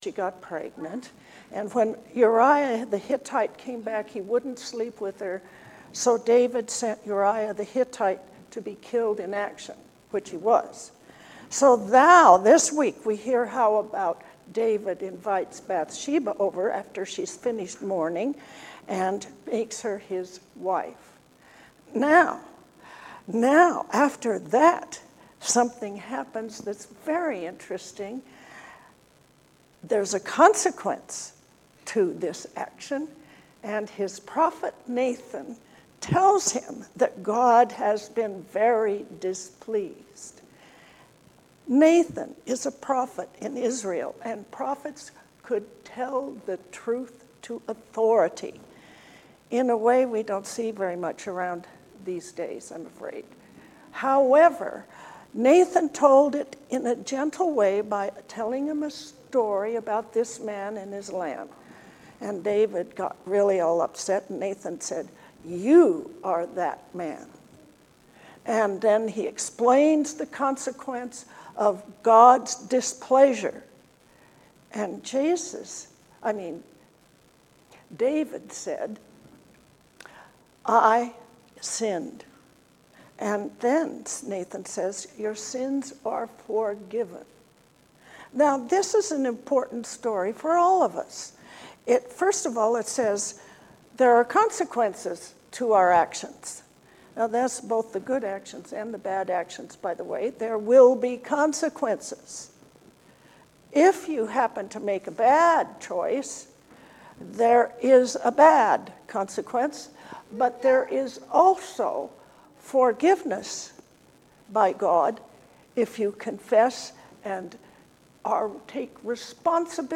Sermon for the Eleventh Sunday after Pentecost